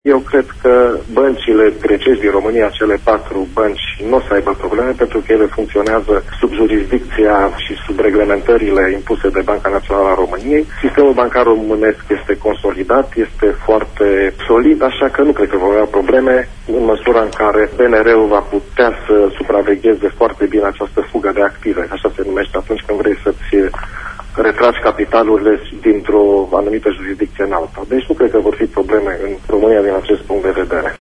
invitat astăzi la emisiunea “Pulsul zilei” crede că românii nu trebuie să își facă probleme